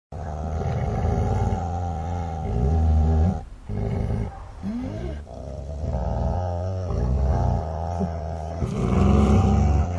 growling_sample.wav